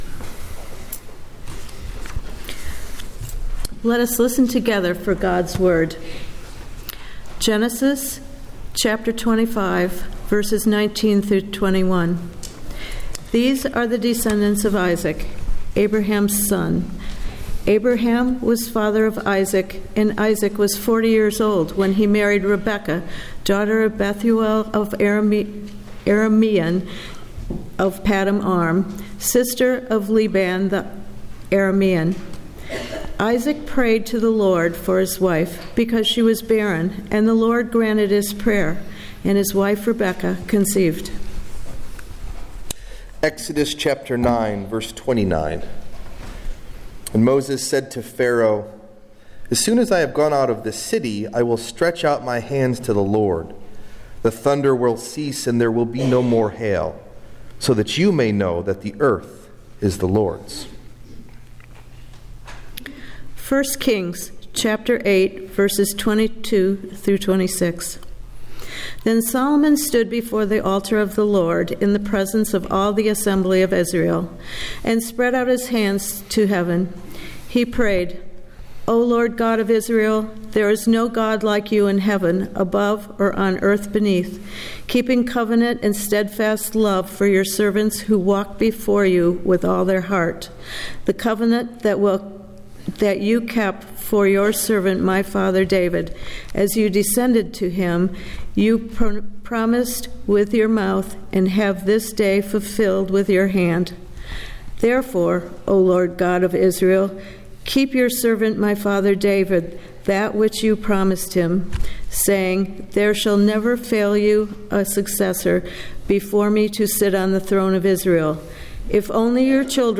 Message Delivered at: The United Church of Underhill (UCC and UMC)
Date: March 9th, 2014 , (Lent 1) Message Delivered at: The United Church of Underhill (UCC and UMC) Key Text(s): Selected readings on prayer This sermon is the first of a three part series on prayer. Today we ask together: why do followers of Jesus Christ pray?